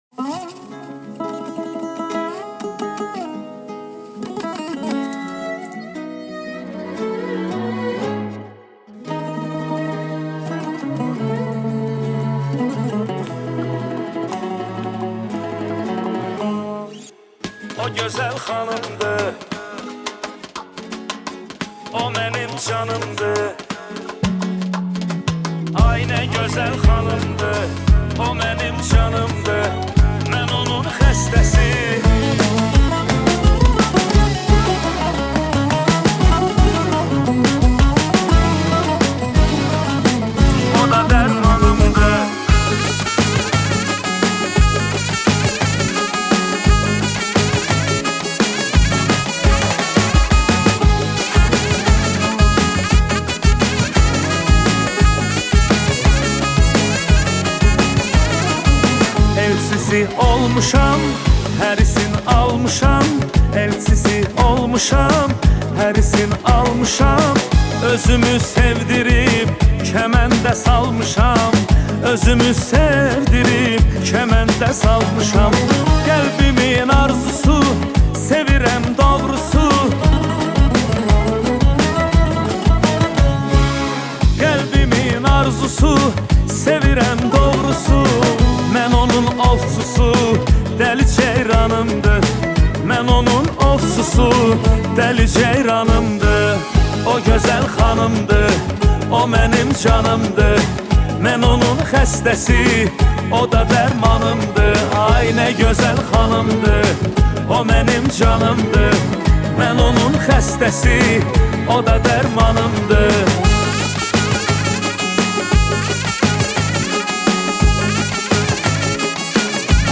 آهنگ آذربایجانی آهنگ شاد آذربایجانی